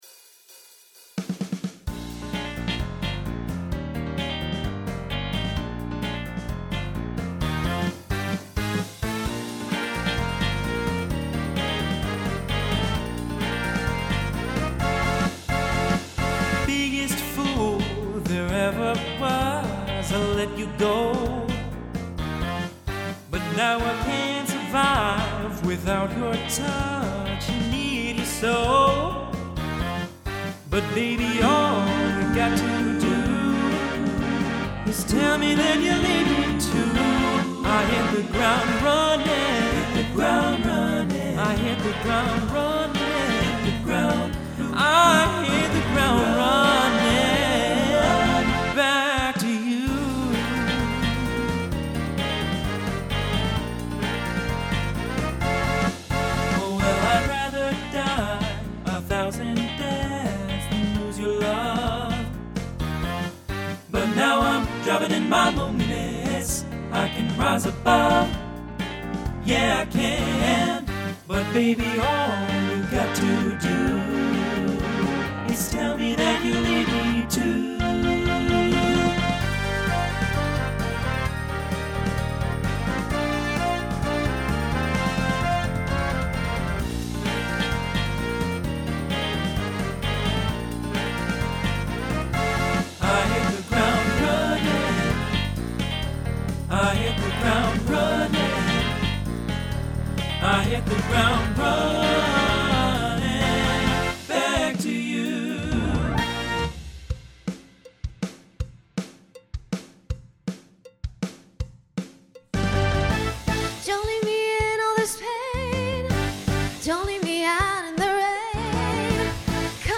(TTB)
(SSA)
Genre Pop/Dance
Transition Voicing Mixed